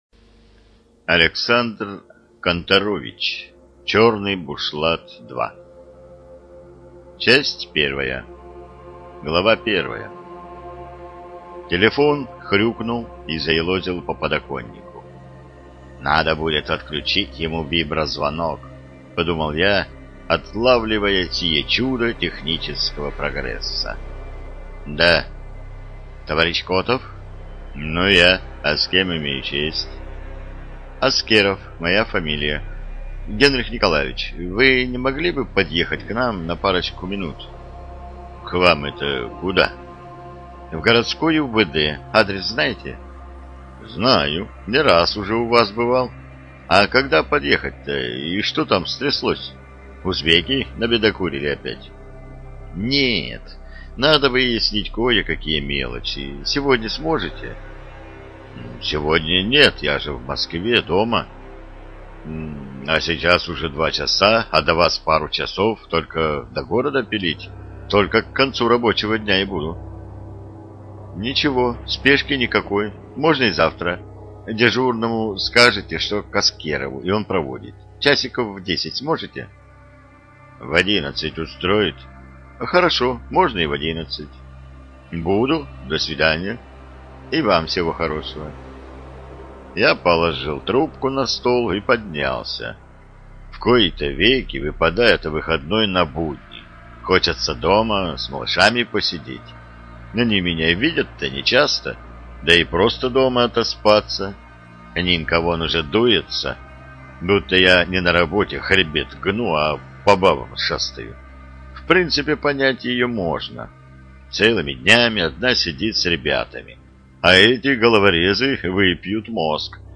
ЖанрАльтернативная история